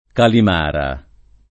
Calimala [kalim#la] top. (a Firenze) — con C‑ maiusc. anche per l’arte di C., che in questa strada (la Calle Mala [k#lle m#la] del sec. XII) aveva il suo centro — come nome della strada, non dell’arte, alterato dal ’400 al primo ’900 in Calimara [